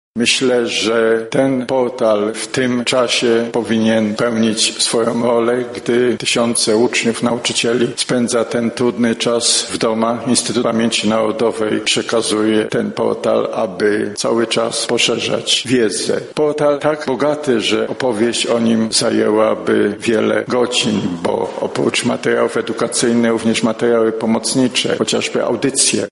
• mówi Jarosław Szarek, prezes IPN.